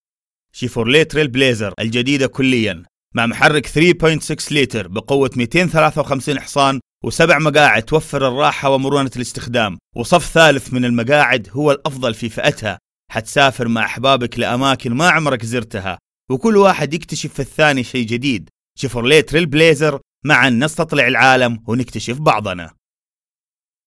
Suudi Arapçası Seslendirme
Erkek Ses